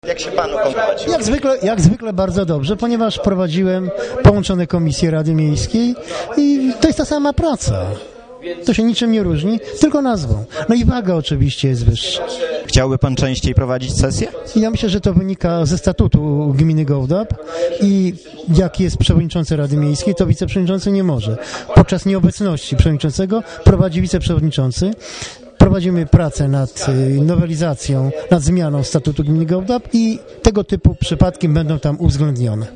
Dziś w Urzędzie Miejskim o godzinie 16 odbyła się nadzwyczajna sesja Rady Miejskiej.
Choć radny starał się tego nie okazywać to dawało się wyczuć pewną tremę w jego głosie podczas obrad.
Mówi Marian Mioduszewski, wiceprzewodniczący Rady Miejskiej